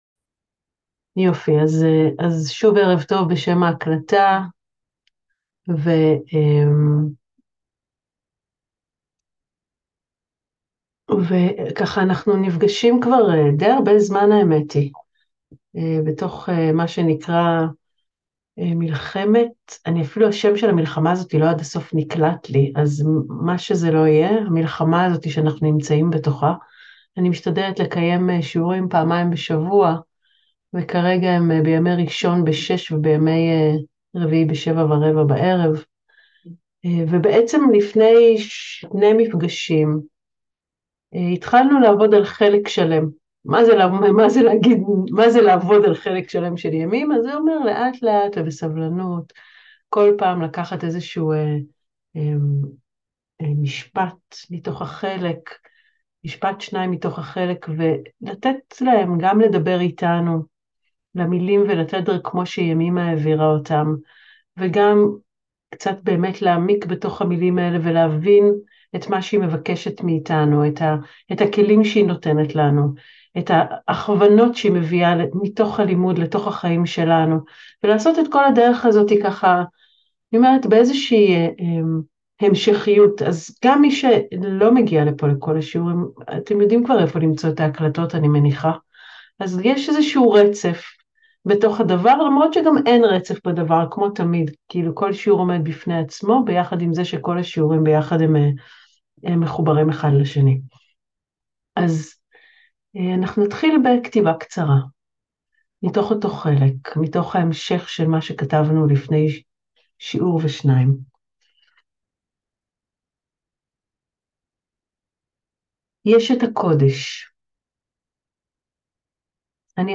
שיעור נוסף לימי מלחמה